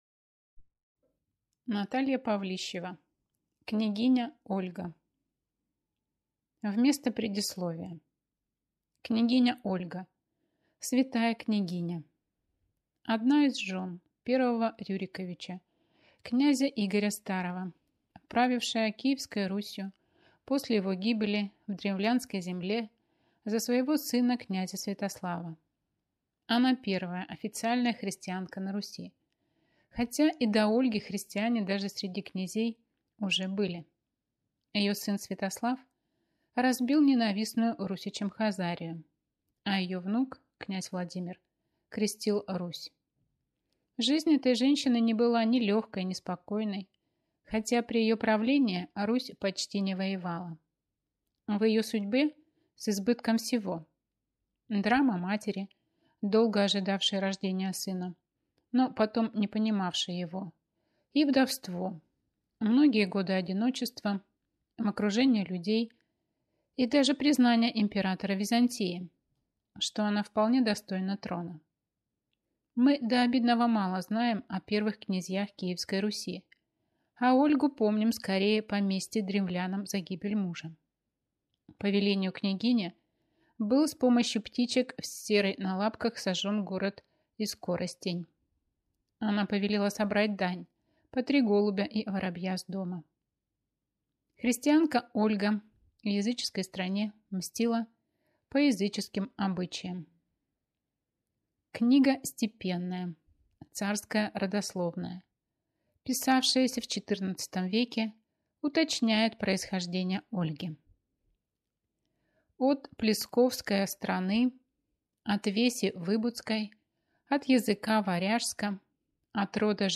Аудиокнига Княгиня Ольга. Обжигающая любовь | Библиотека аудиокниг